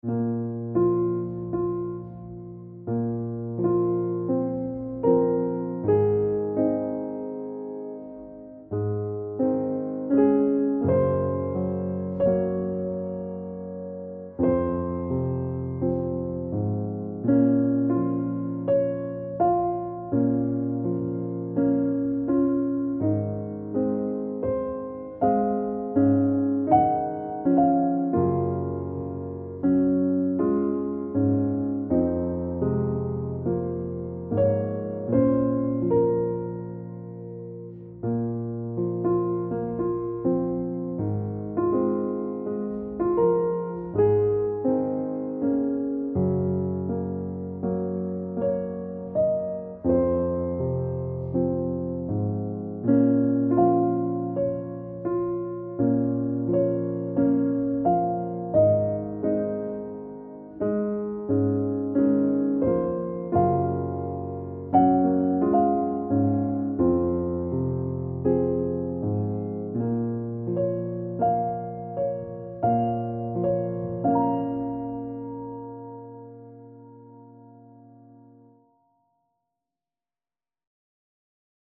piano - triste - romantique - melancolique - tristesse